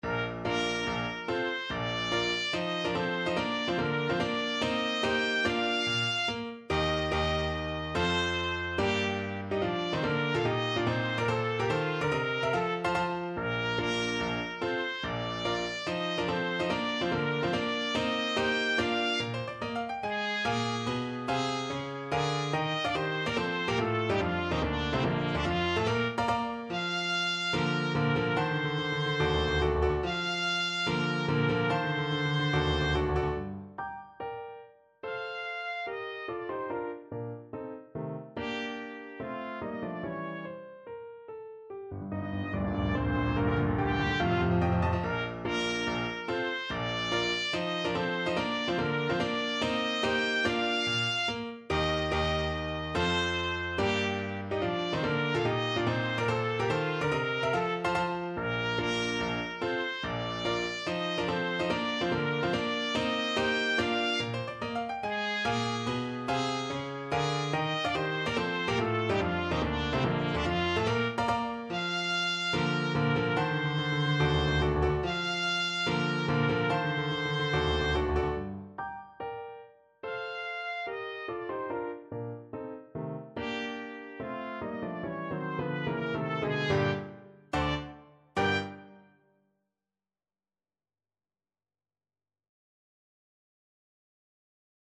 Trumpet version
Allegro non troppo (=72) (View more music marked Allegro)
4/4 (View more 4/4 Music)
Bb4-G6
Classical (View more Classical Trumpet Music)